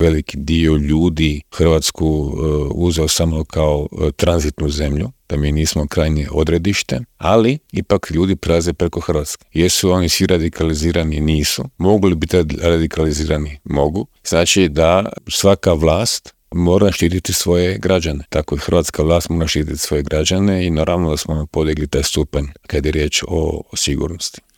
Aktualnu situaciju na Bliskom istoku, ali i u Europi, u Intervjuu Media servisa analizao je bivši ministar vanjskih i europskih poslova Miro Kovač.